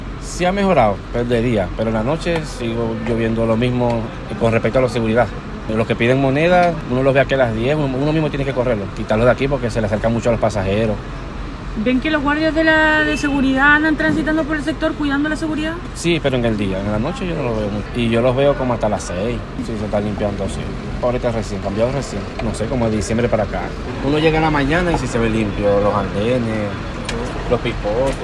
La Radio conversó con diversos trabajadores y pasajeros al interior del rodoviario, quienes comentaban la falta de seguridad en el recinto, como la principal causante de los problemas que se registran.
Una persona que trabaja en los puestos de los andenes señaló que la higiene ha mejorado, pero que pasado las 6 de la tarde no ven ronda de guardias afuera.
trabajador-en-el-anden.mp3